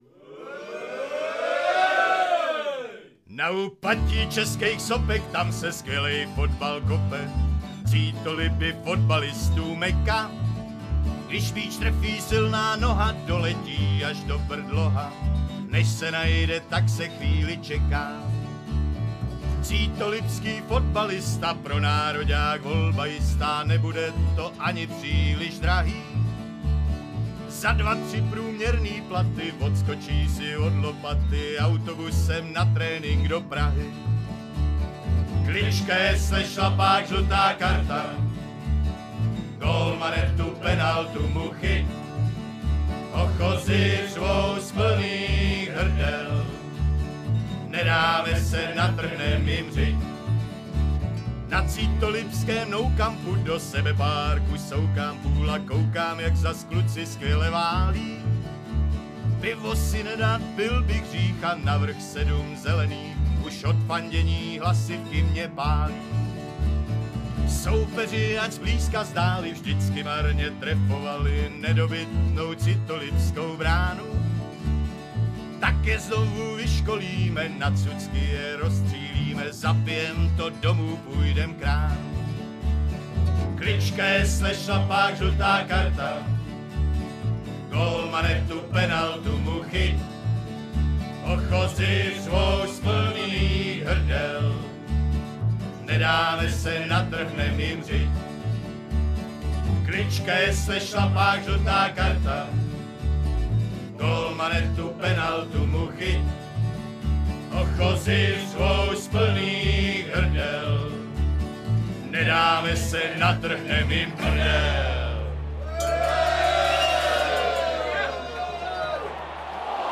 Klubové suvenýry: Minidres SK Cítoliby s číslem 55,- Kč Vlajka SK Cítoliby 30,- Kč Šála SK Cítoliby 130,- Kč Čepice SK Cítoliby 100,- Kč Propisovačka SK Cítoliby 10,- Kč Klubová hymna ke stažení